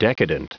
Prononciation du mot decadent en anglais (fichier audio)
Prononciation du mot : decadent